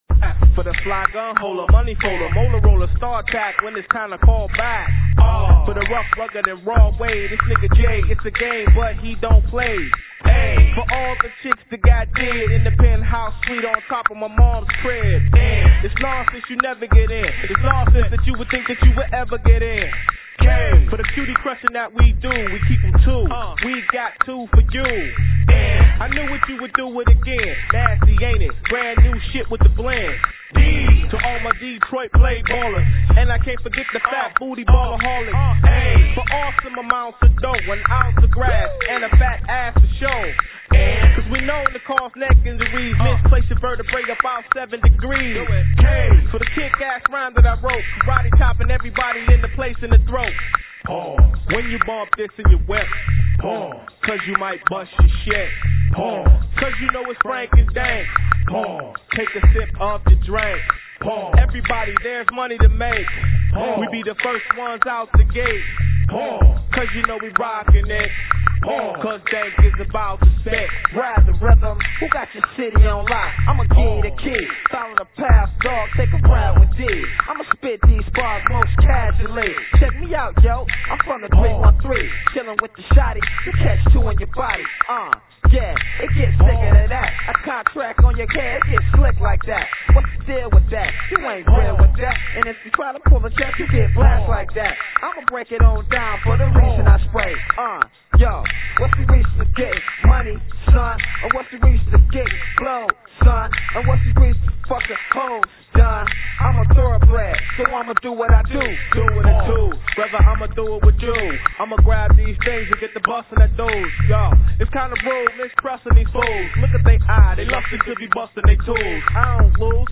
Sorry about the low encoding quality but it saves space!